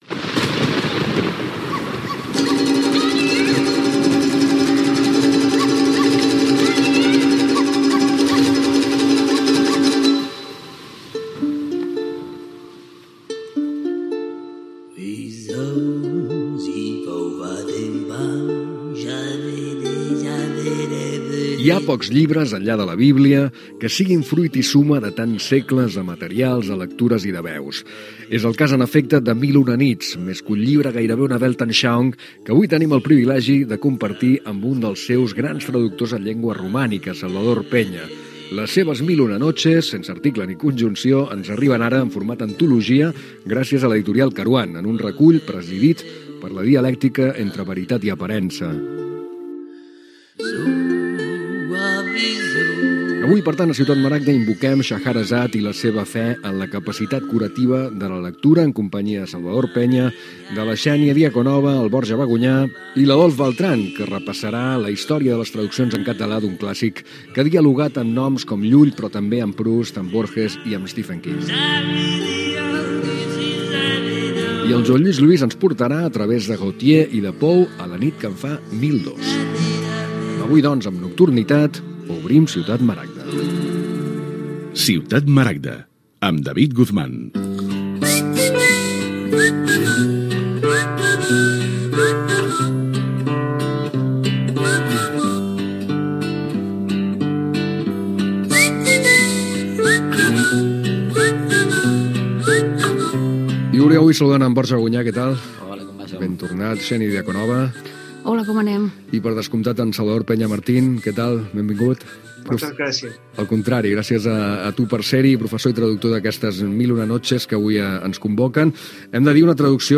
Presentació del programa, equip, sumari, indicatiu, entrevista